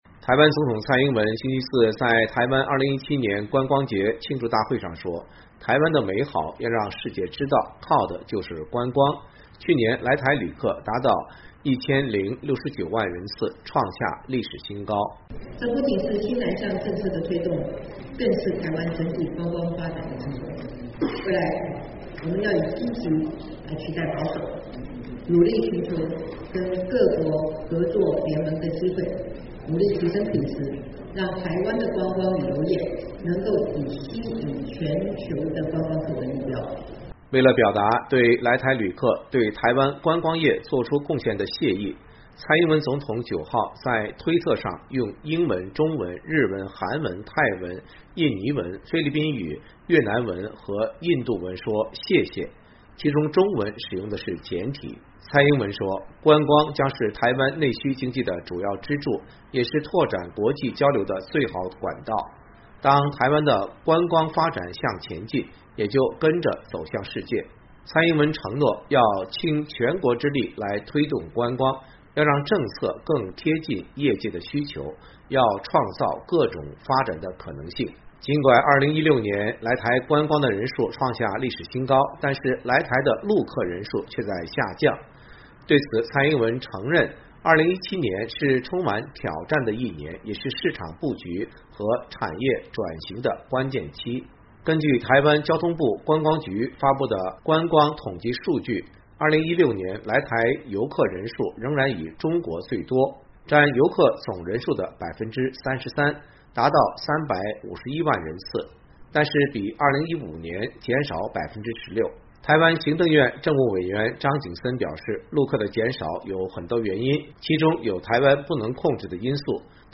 台湾总统蔡英文在2017年观光节庆祝大会上致词